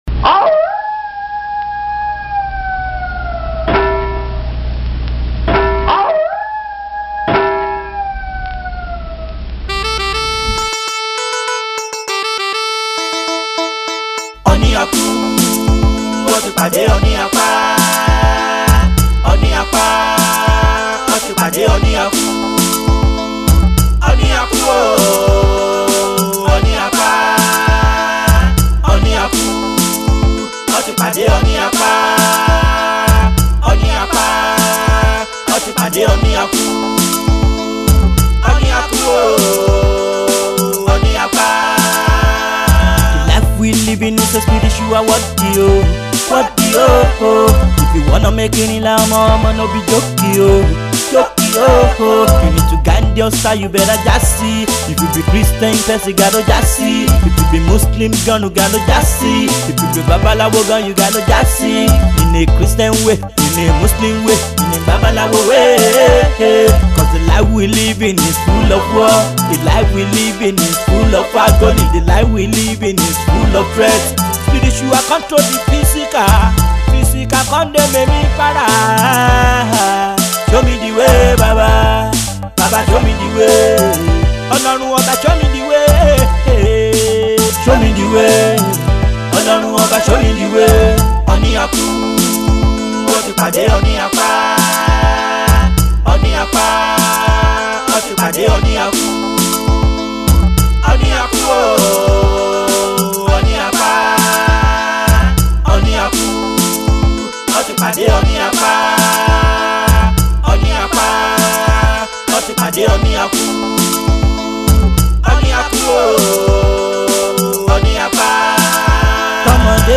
Neo-Fuji, Street Blues
not well produced